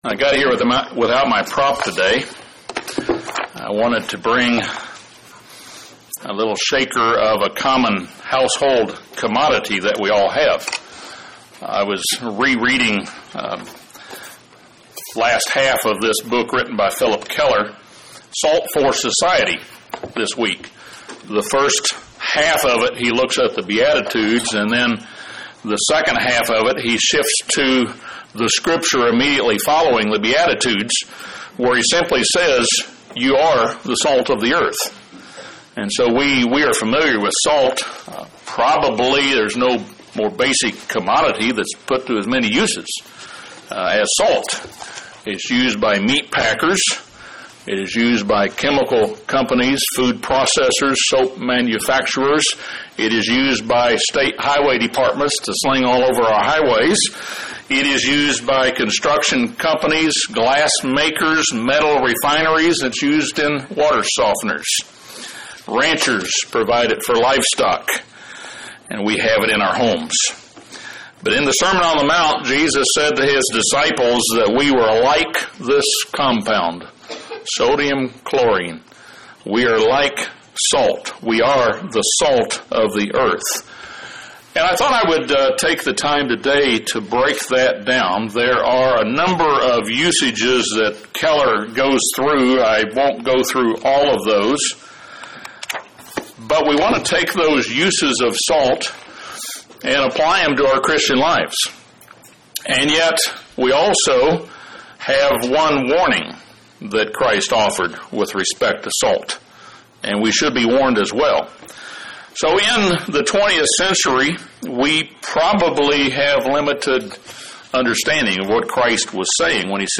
During the Sermon on the Mount, Jesus compared the disciples to salt. This sermon discusses various positive functions of salt – its importance in maintaining life, the preservation and seasoning of food, and in facilitating healing.